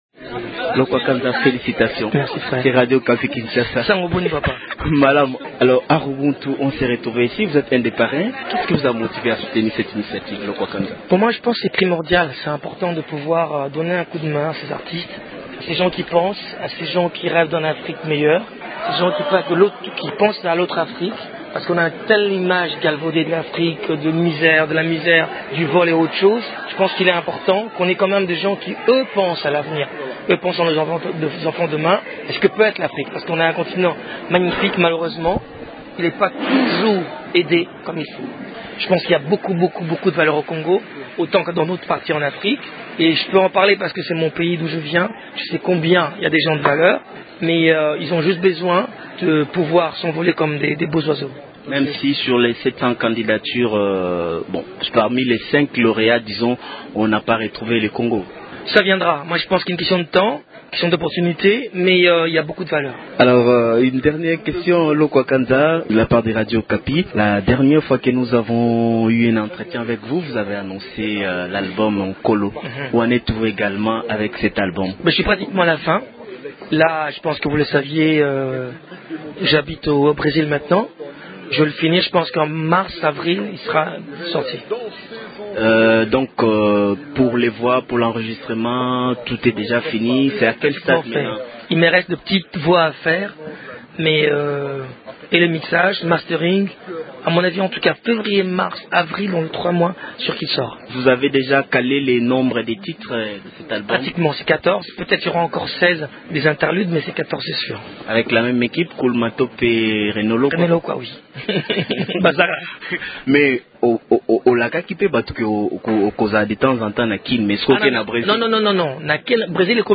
Pascal Lokwa Kanza qui prépare la sortie de son prochain album « Nkolo », vient de s’installer au Brésil. Il se confie au micro
lors de la soirée Harubuntu qui s’est tenu à Bruxelles le 20 octobre dernier.